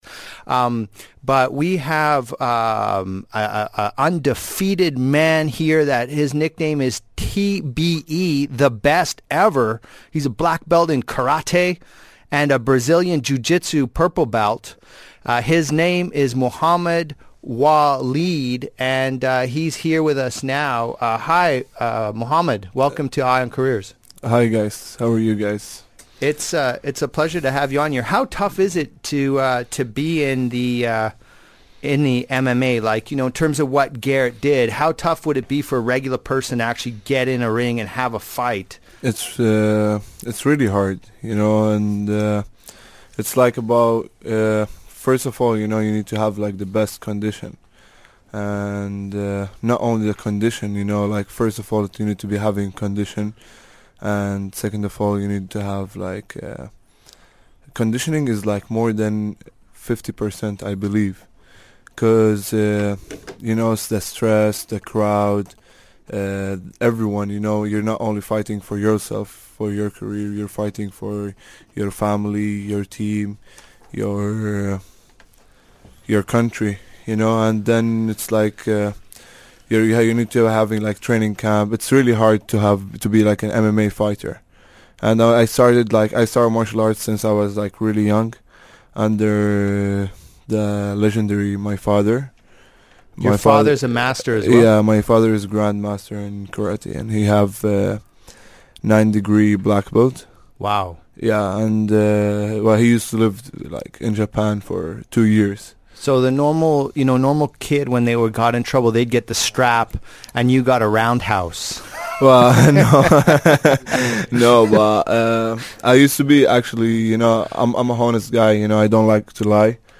an HR expert